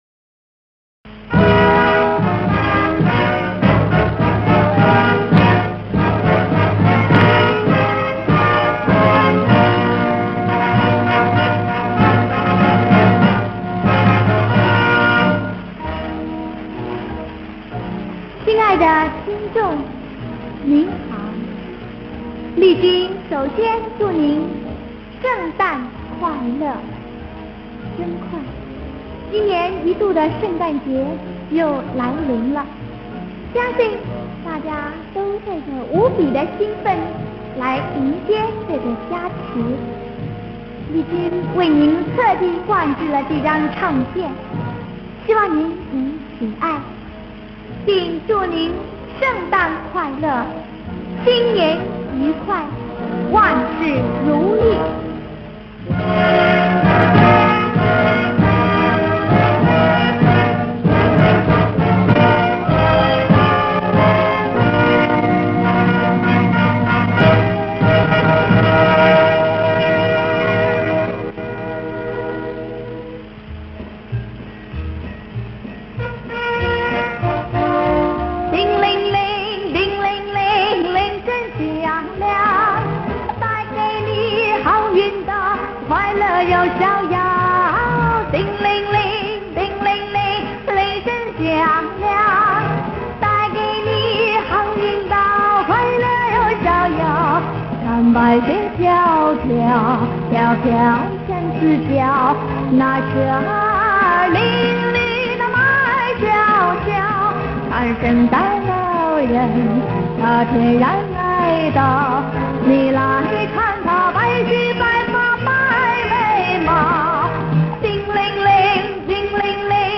贺词、圣诞铃声、圣诞老人笑哈哈